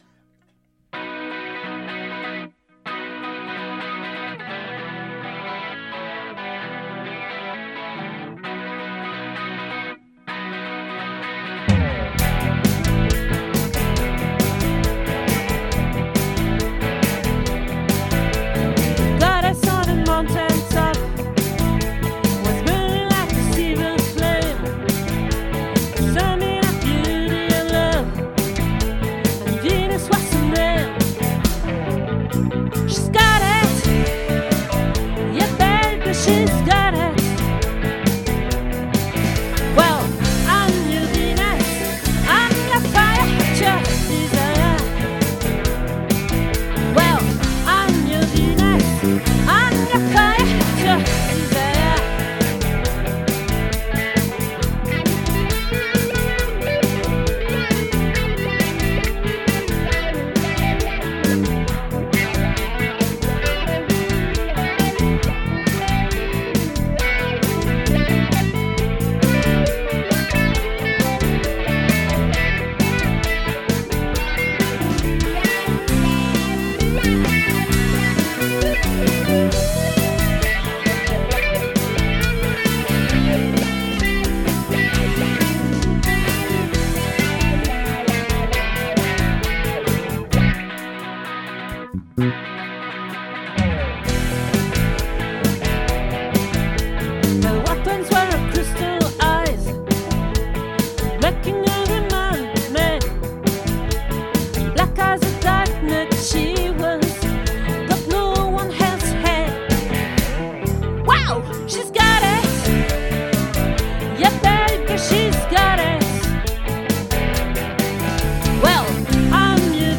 🏠 Accueil Repetitions Records_2022_10_12